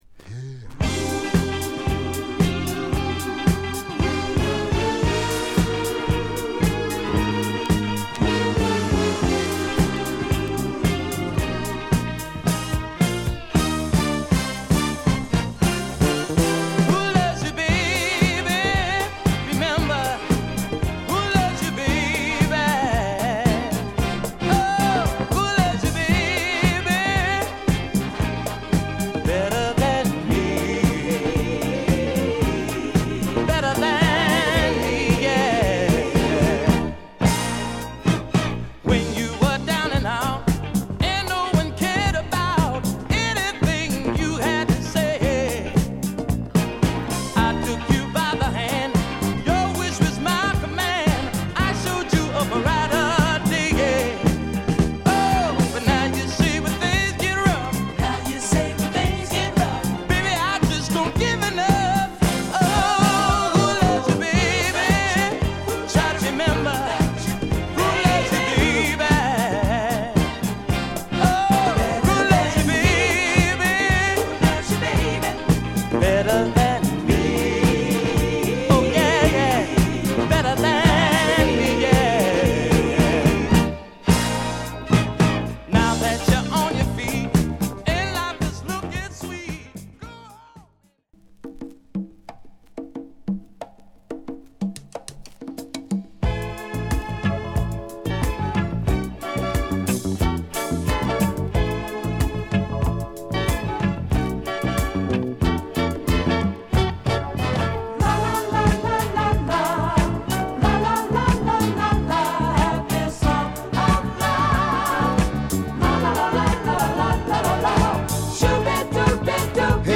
男性ヴォーカルグループ
前作よりも洗練されたモダンなソウルを披露